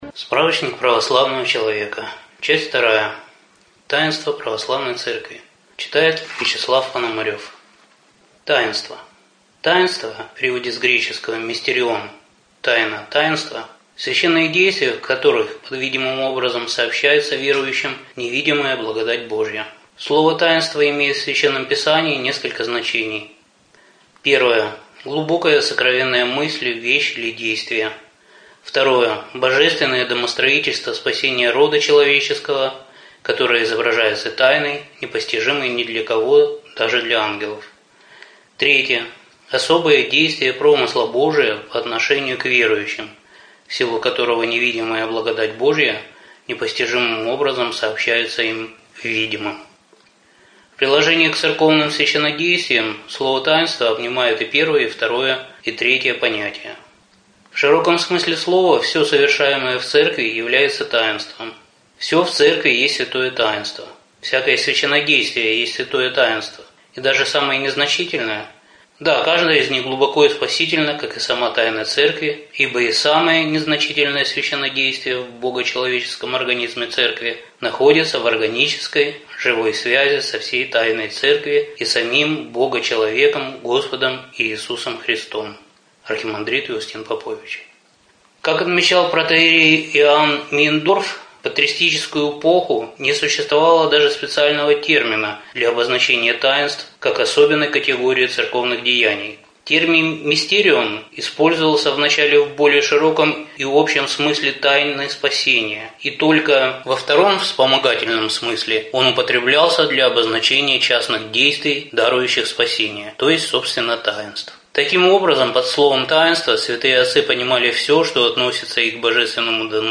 Аудиокнига Справочник православного христианина. Часть 2. Таинства Церкви.
Прослушать и бесплатно скачать фрагмент аудиокниги